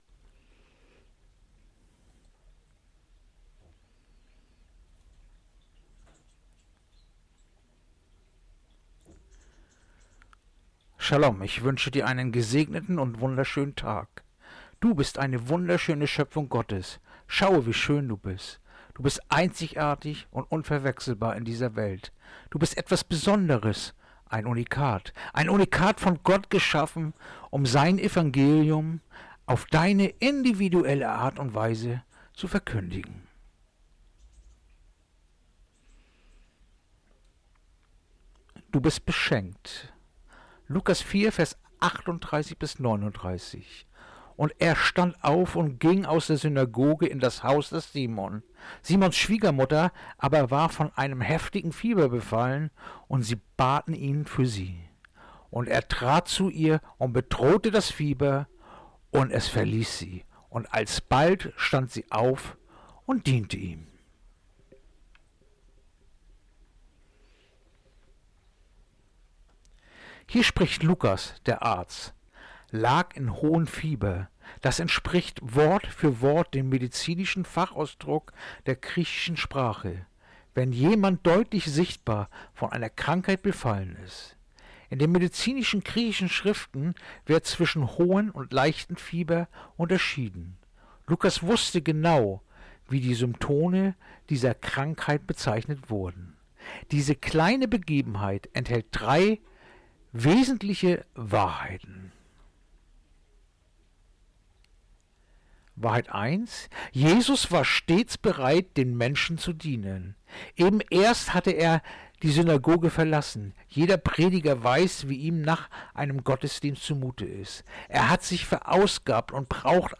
Andacht-vom-06.-Januar-Lukas-4-38-39